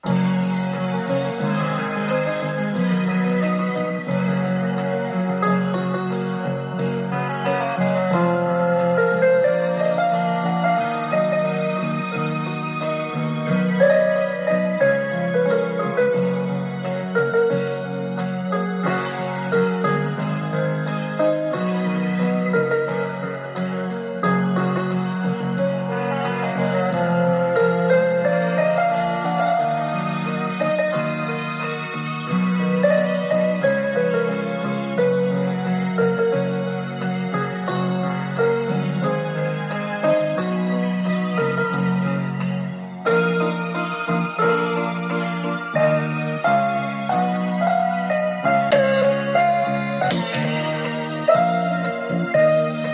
They all come from various audio cassettes.